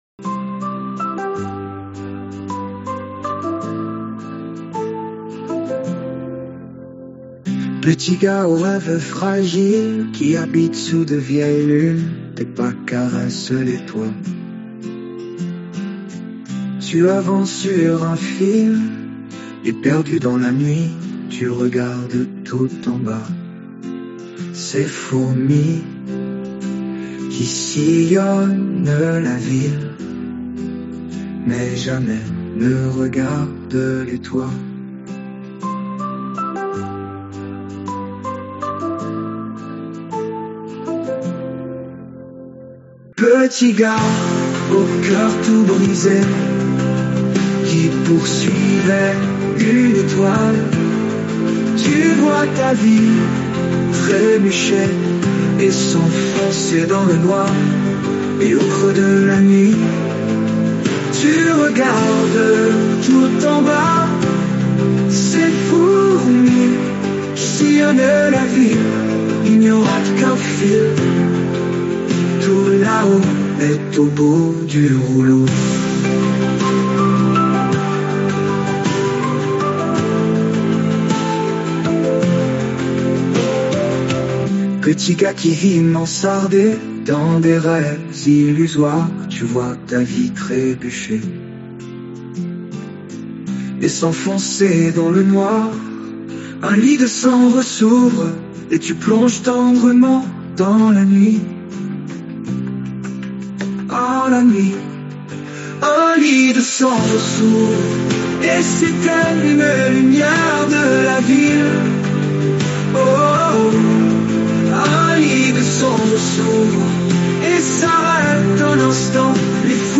Interface Suno AI